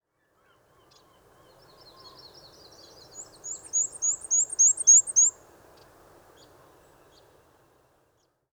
Paruline noir et blanc – Mniotilta varia
Chant Dunes de Tadoussac, QC, 48°09’39.2″N 69°39’32.2″W. 27 mai 2018. matin.